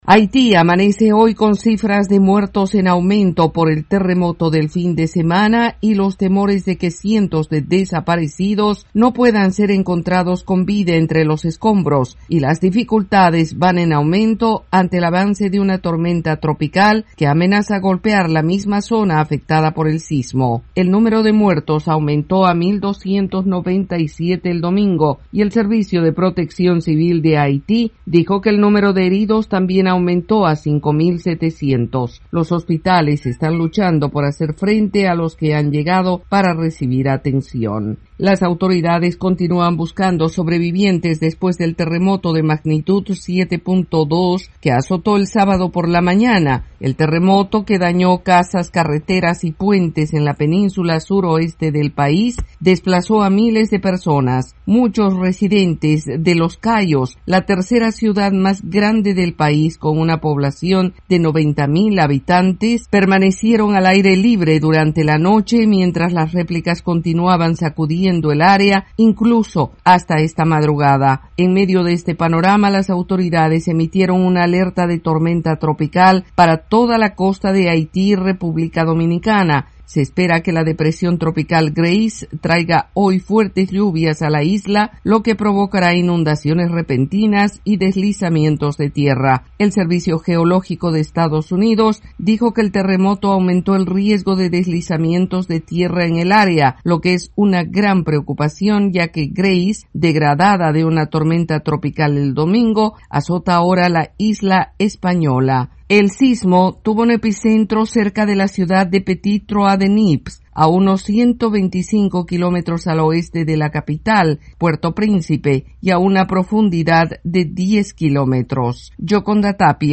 desde la Voz de América en Washington DC.